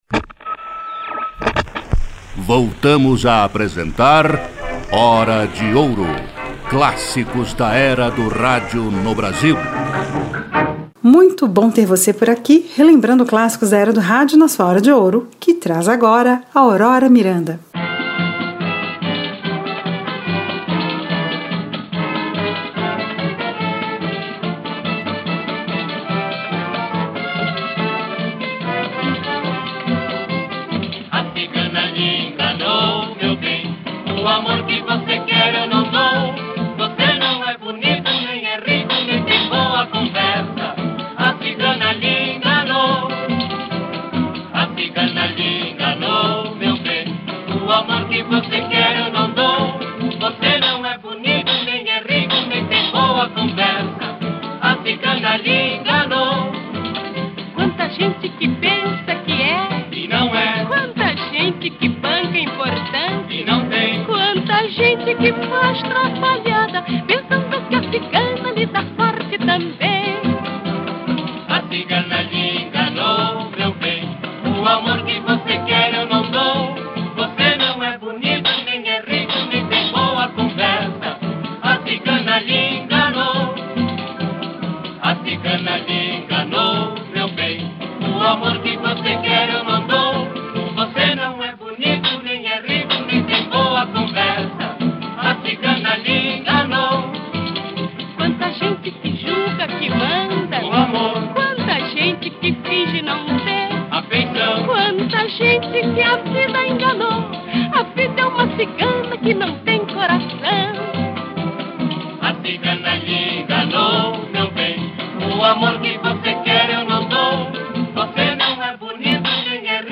conjunto vocal